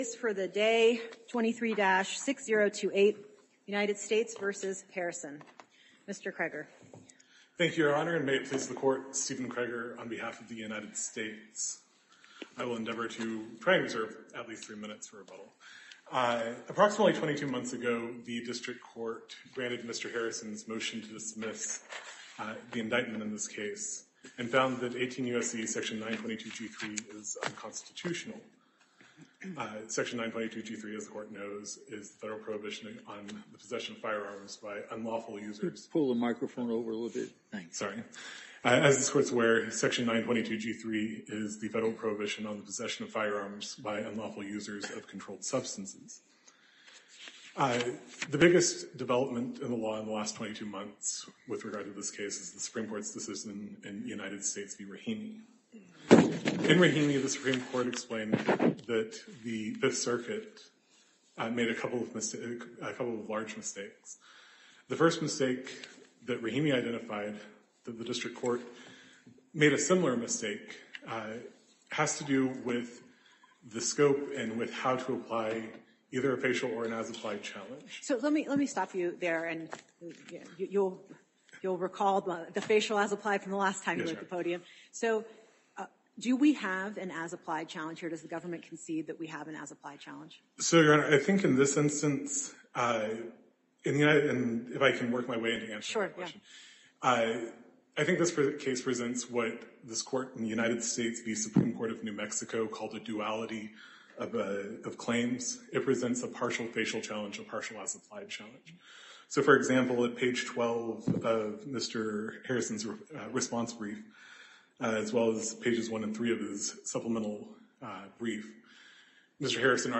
A panel of judges on the U.S. Court of Appeals for the Tenth Circuit heard oral arguments last week in the case, questioning not only the firearms prohibition itself but also whether it’s within the scope of the appeals panel’s power to review the underlying lower court’s decision.